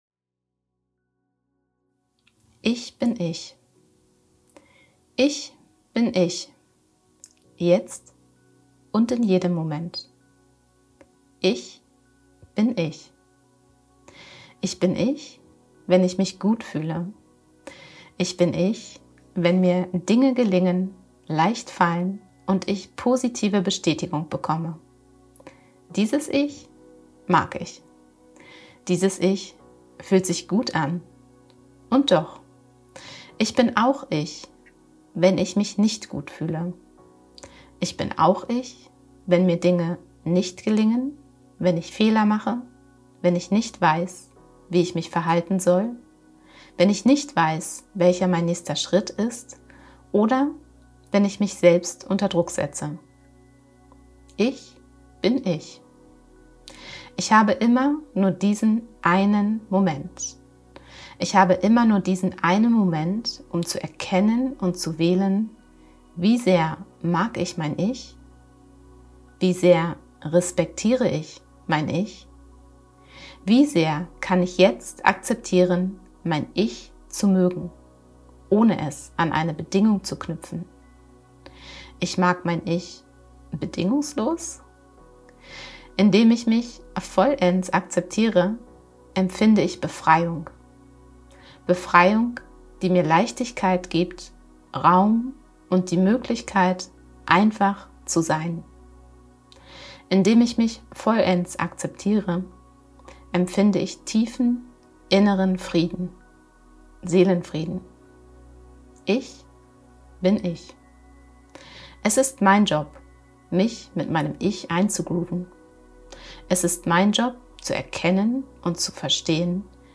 Ich-bin-Ich_mit-Musik.mp3